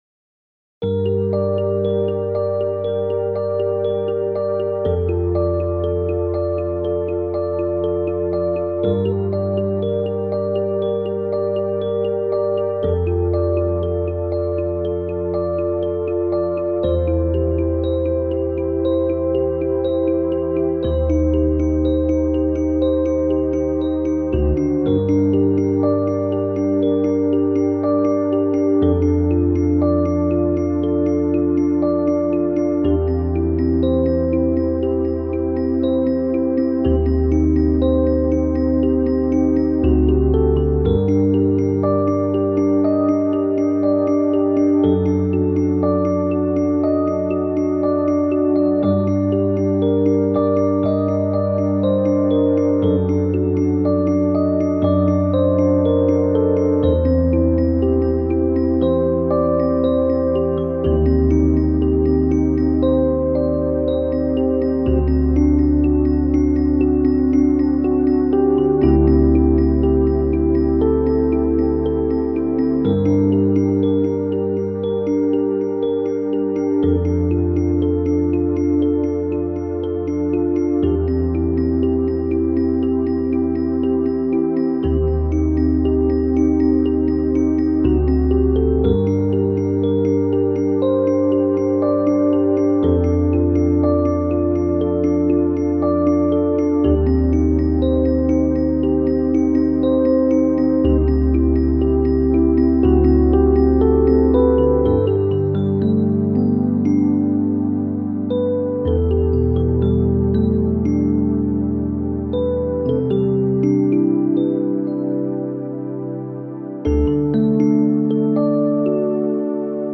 Synthesizer MIX
Jupiter-Programms-1.mp3